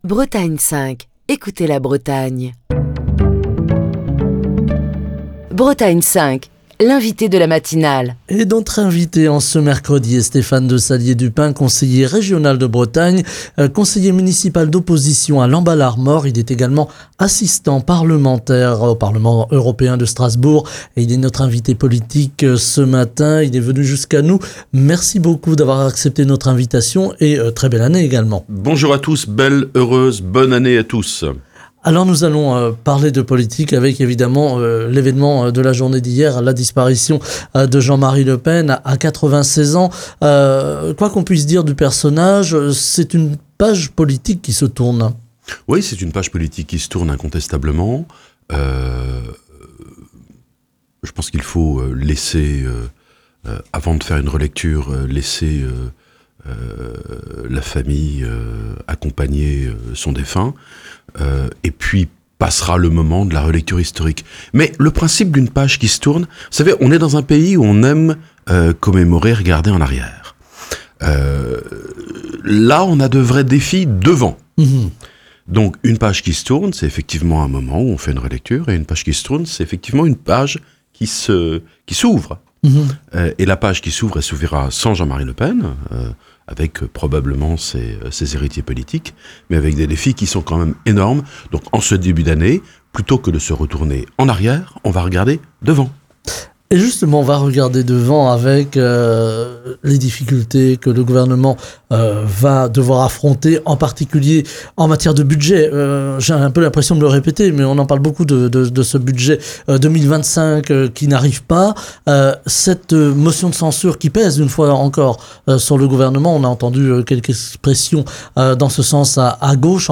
Ce matin, Stéphane de Sallier Dupin, conseiller régional de Bretagne, conseiller municipal de Lamballe-Armor et assistant parlementaire au Parlement européen à Strasbourg, était l'invité politique de la matinale de Bretagne 5. Stéphane de Sallier Dupin a abordé plusieurs sujets d'actualité, à commencer par le décès de Jean-Marie Le Pen.